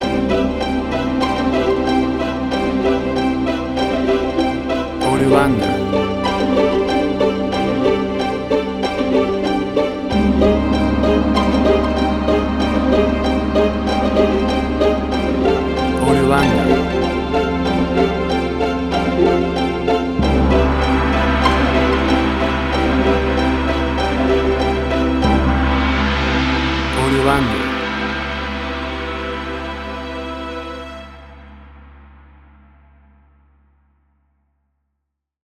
WAV Sample Rate: 24-Bit stereo, 48.0 kHz
Tempo (BPM): 96